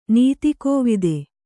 ♪ nīti kōvide